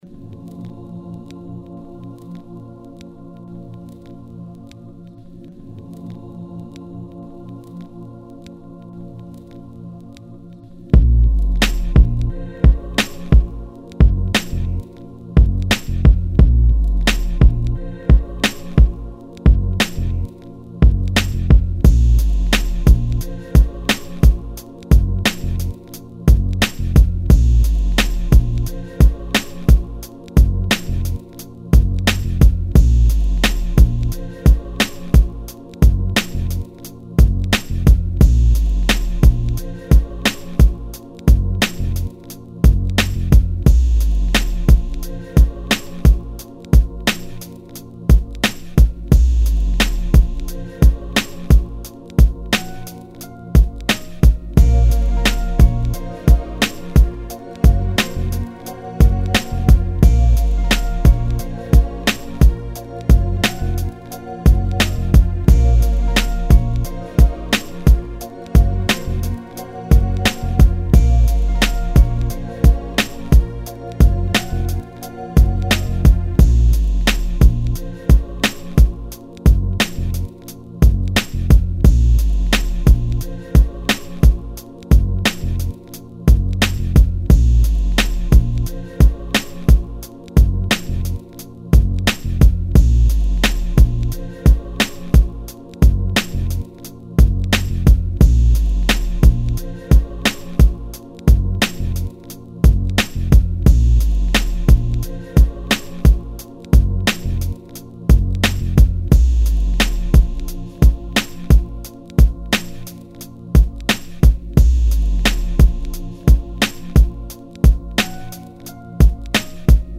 88 BPM.